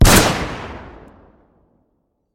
shot3.mp3